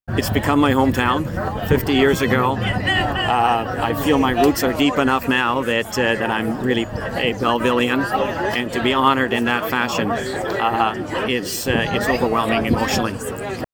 Wednesday, Belleville Mayor Mitch Panciuk along with city councillors and invited guests, honoured the retired Superior Court of Justice judge and former Belleville Council member, with a plaque dedication in the Commons Area on Front Street, across from City Hall.